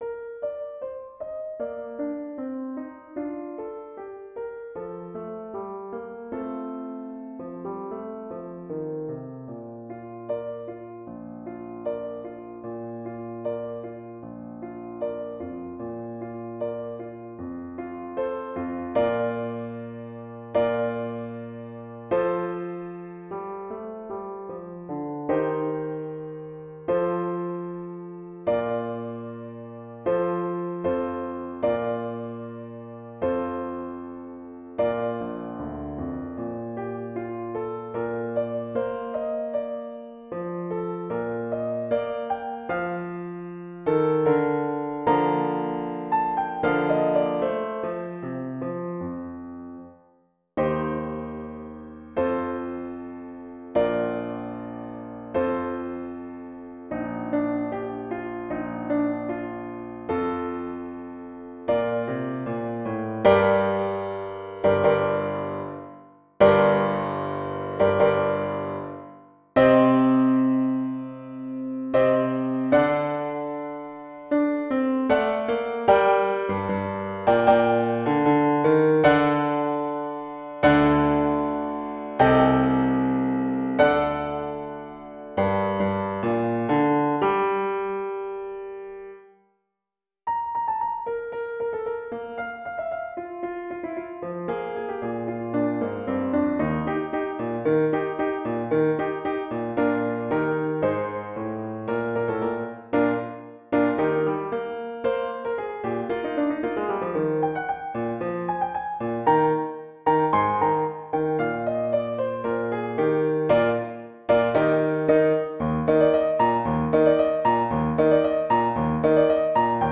Audio: Piano part alone